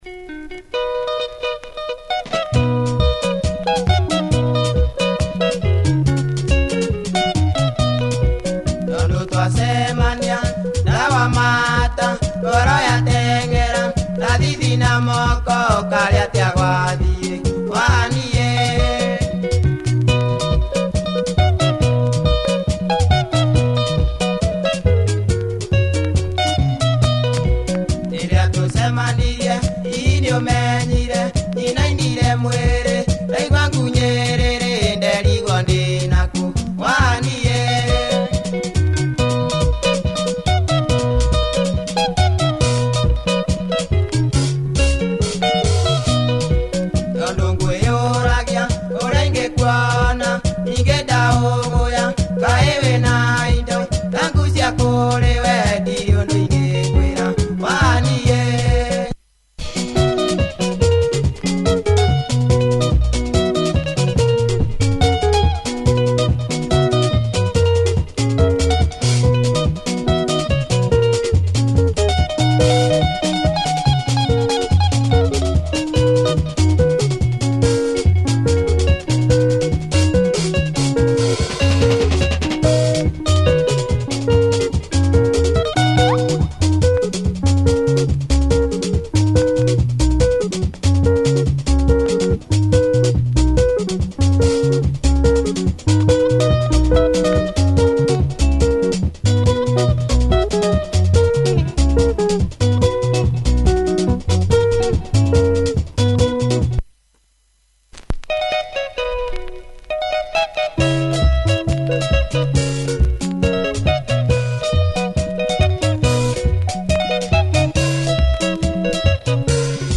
Nice Kikuyu benga, check audio of both sides! https